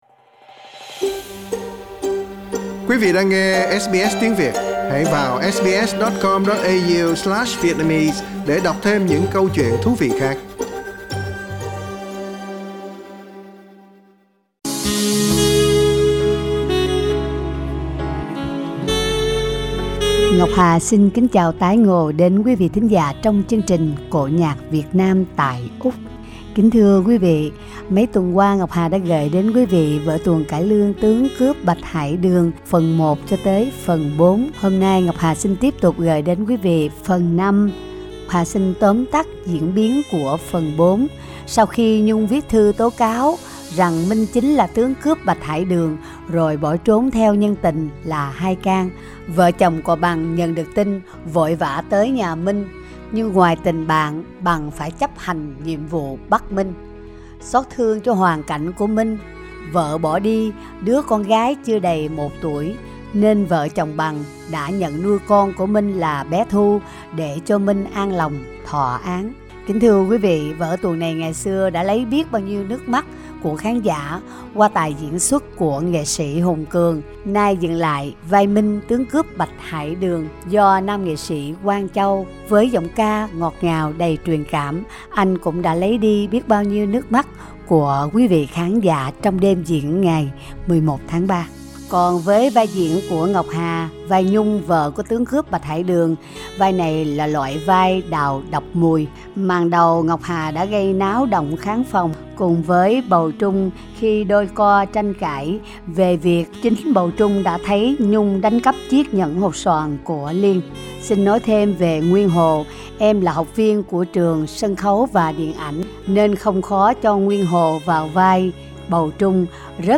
Xin mời quý vị cùng theo dõi tiếp phần 5 vở tuồng Cải Lương Tướng cướp Bạch Hải Đường, do anh chị em nghệ sĩ Úc Châu trình diễn tại Sydney.